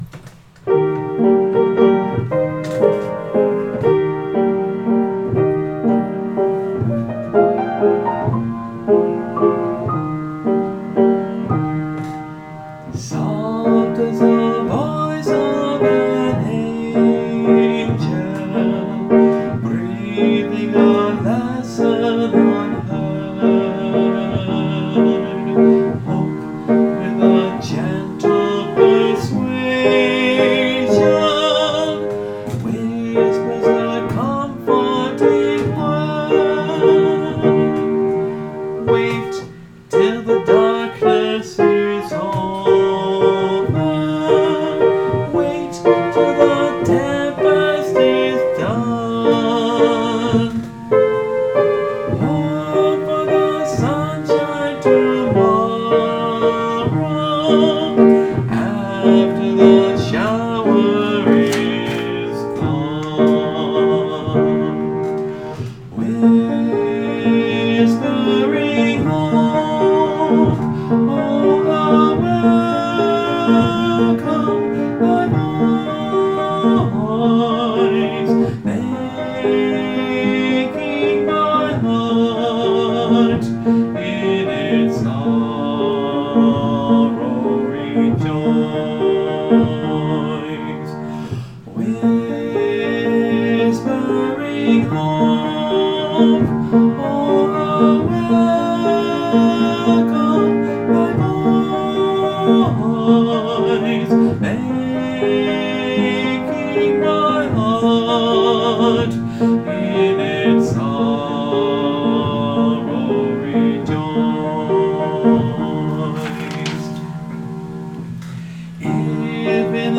But it has a nice melody, even if a little expansive.  Need a lot of support, which isn’t that easy to do while playing and singing.